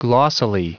Prononciation du mot glossily en anglais (fichier audio)
Prononciation du mot : glossily